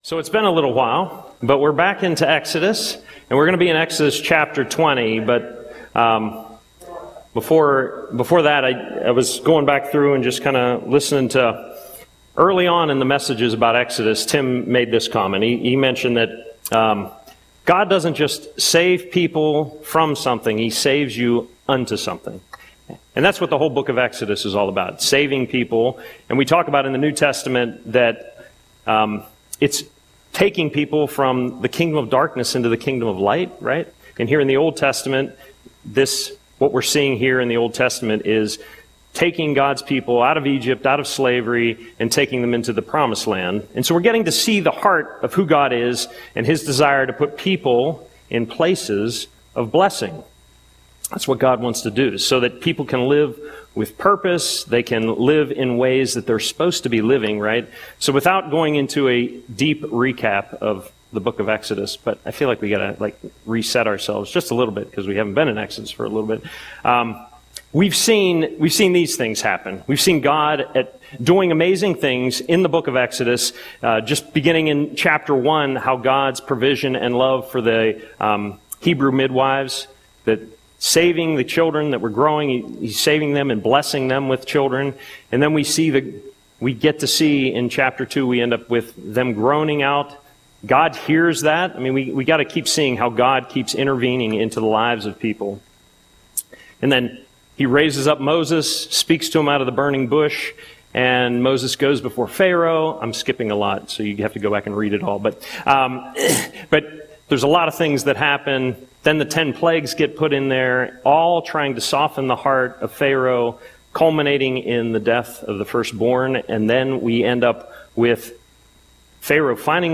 Audio Sermon - April 23, 2025